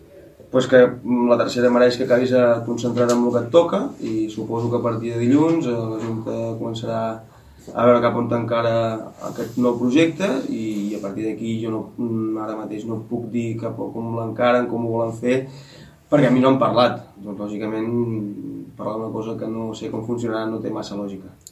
ha assegurat en roda de premsa que els seus no han fet un mal partit